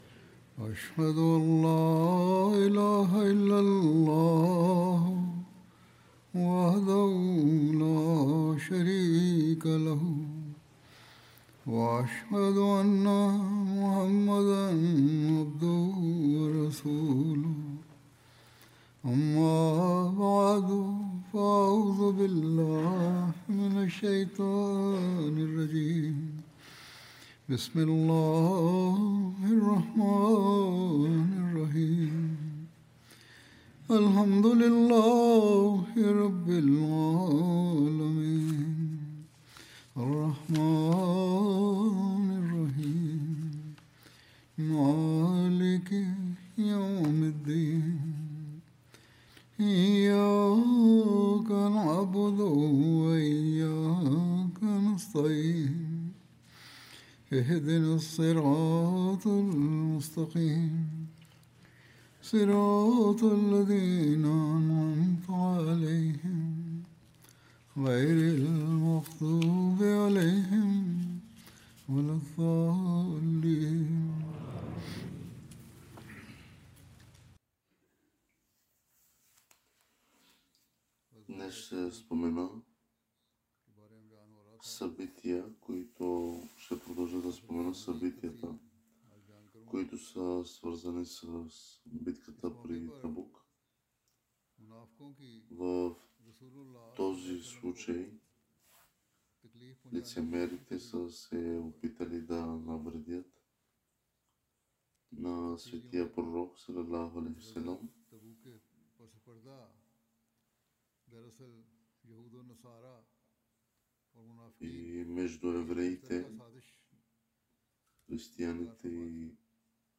Bulgarian translation of Friday Sermon delivered by Khalifa-tul-Masih on November 21st, 2025 (audio)